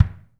BD 1L.wav